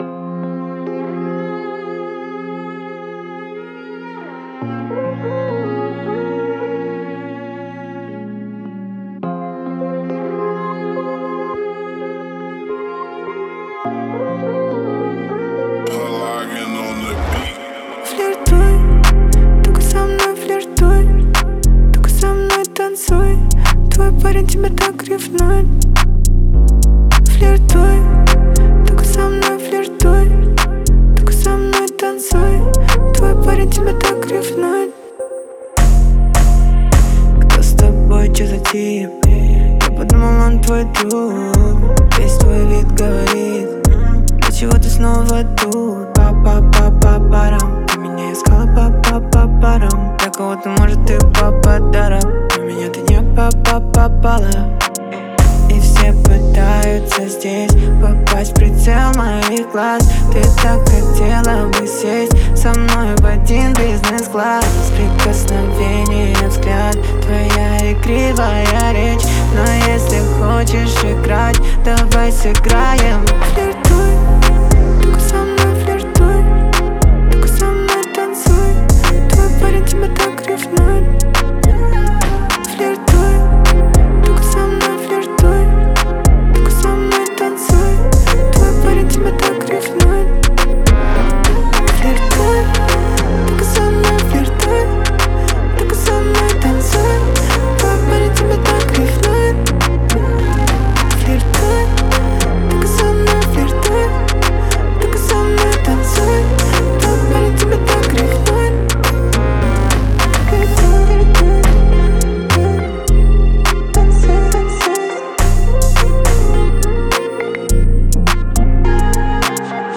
• Жанр: Русские